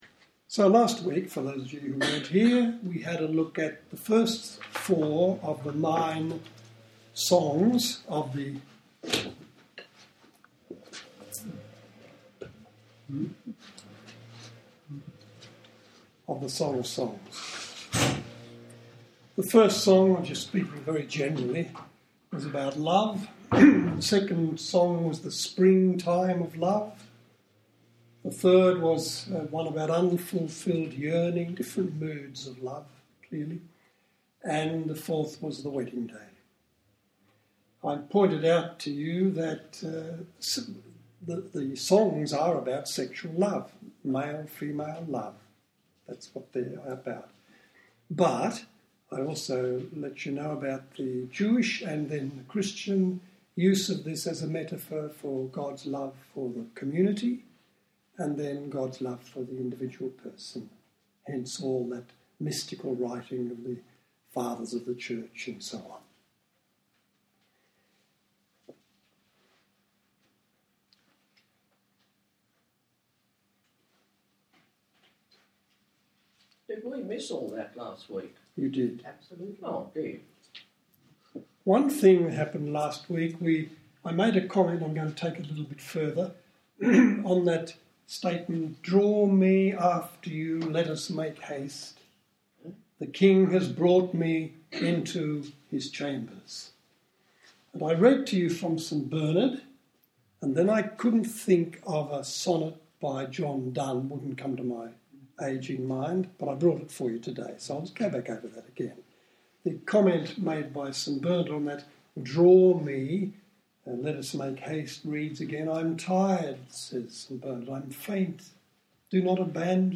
Audio files I offered a series of two lectures in Canberra in 2012, covering the whole Song and also offering examples of how the Song has been used as an expression of God's love for the community and the individual person. 1.